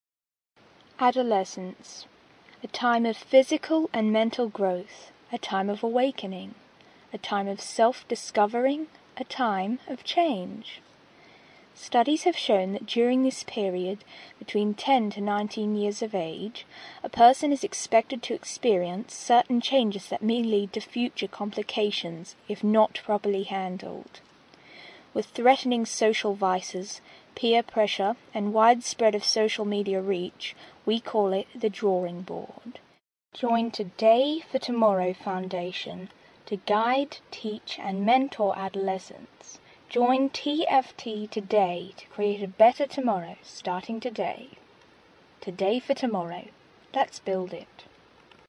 描述：画外音，男人说：“现在，来自克朗代克酒吧的一句话。”
标签： 语音 保荐人 男性 画外音
声道立体声